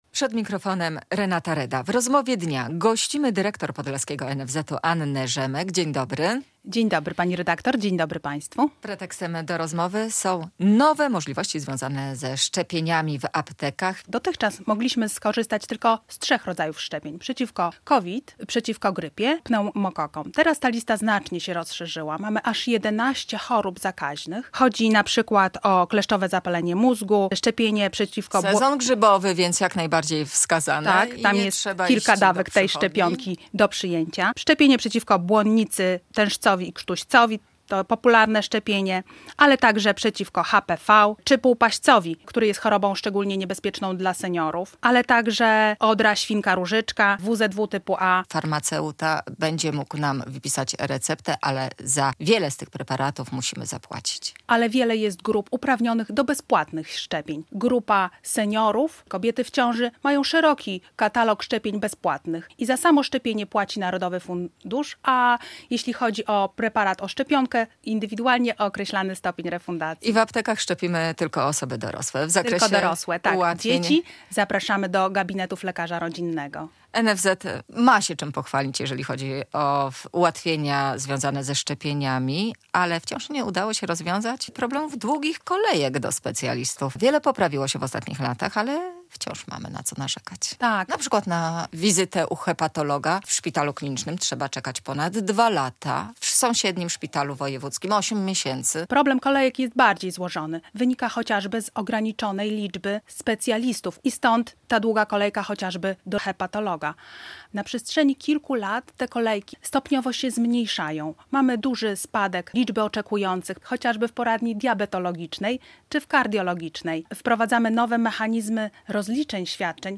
W Rozmowie Dnia w Polskim Radiu Białystok poruszyliśmy też temat nowego programu "Moje Zdrowie".